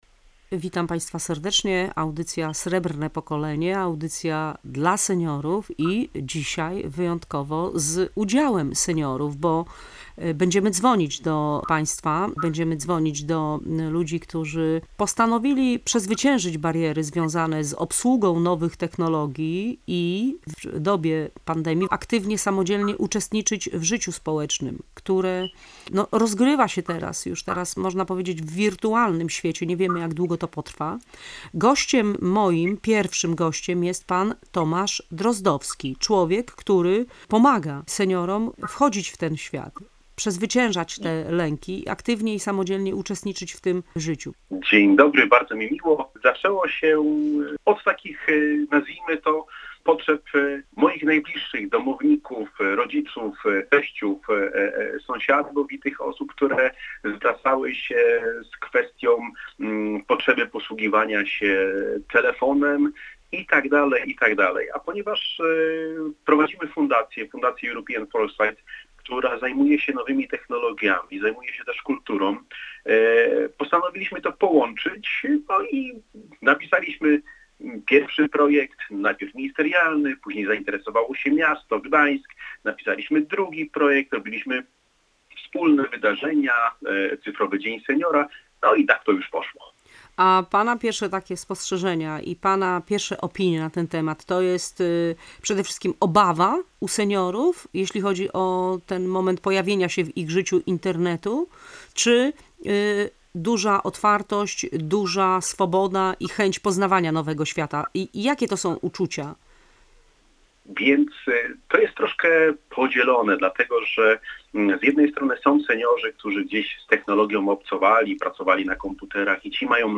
Tym razem w audycji o specjalnych aplikacjach dla seniorów opowiadają informatycy i sami seniorzy.